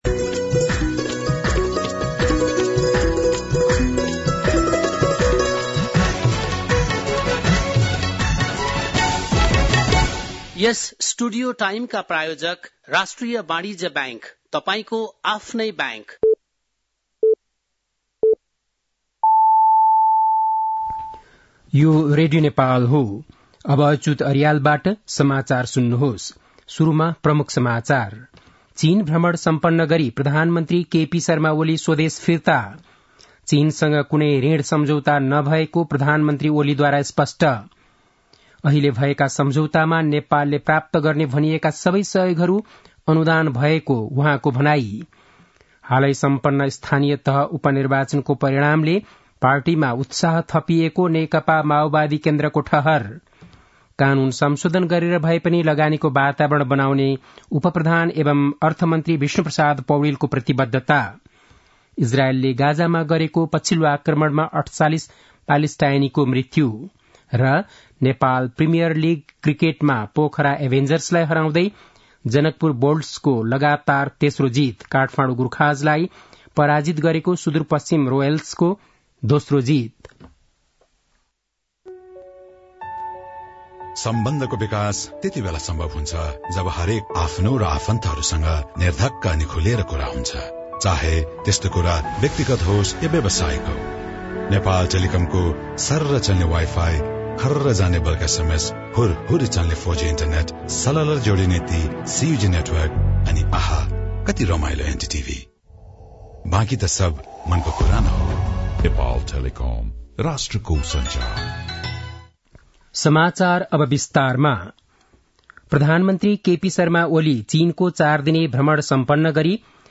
बेलुकी ७ बजेको नेपाली समाचार : २१ मंसिर , २०८१
7-pm-news-8-20.mp3